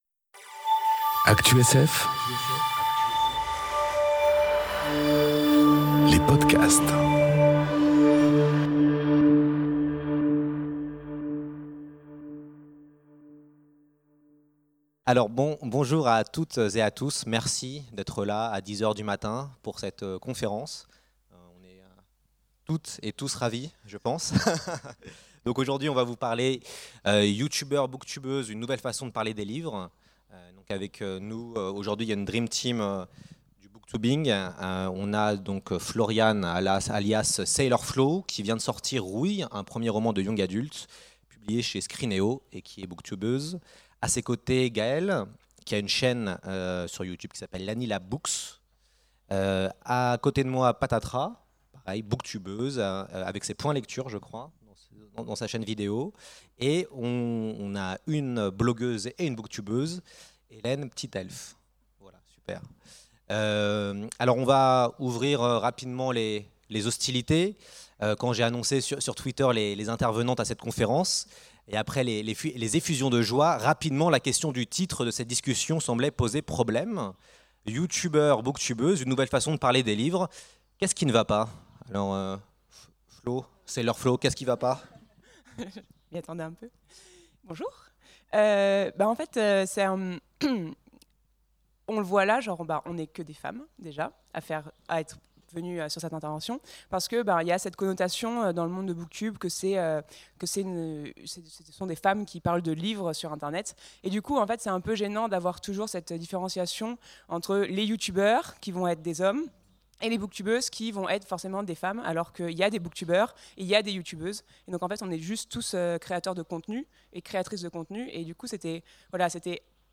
Conférence Youtubeurs, booktubeuses... Une nouvelle façon de parler des livres ? enregistrée aux Imaginales 2018